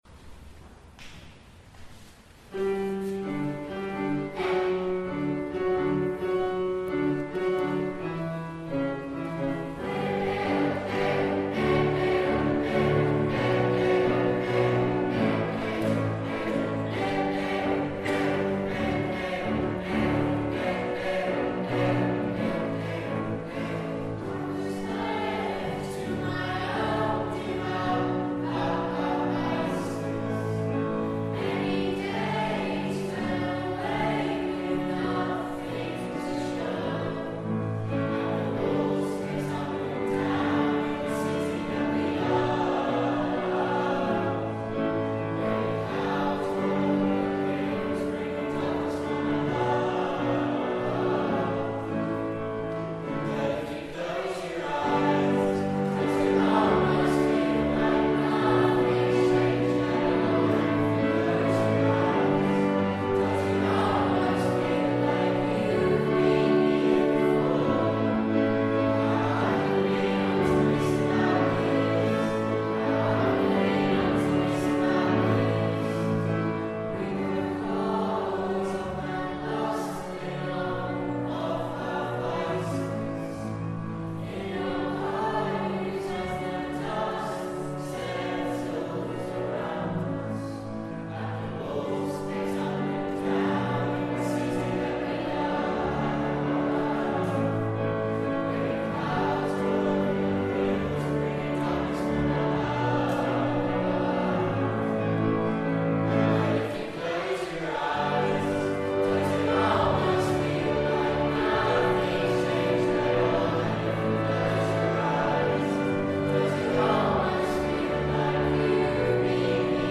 At the Simon Balle Choral Concert 2017